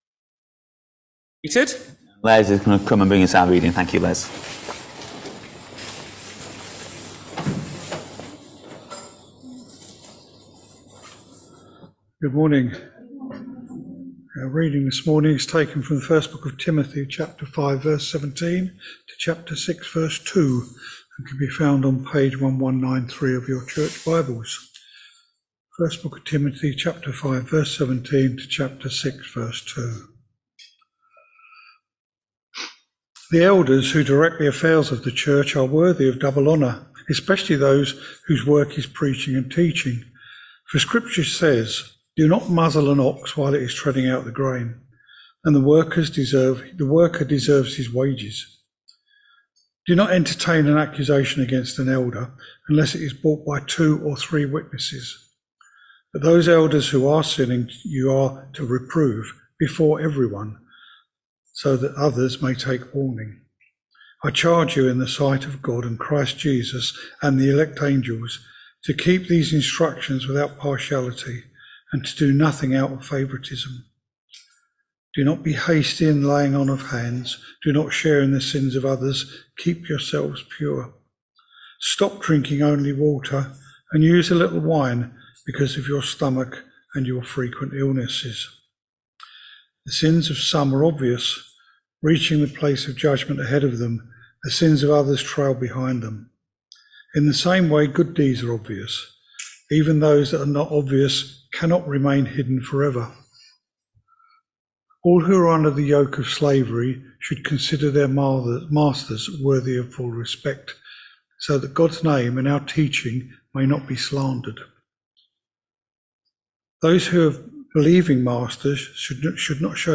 1 Timothy 5v17-6v2 Service Type: Sunday Morning All Age Service Topics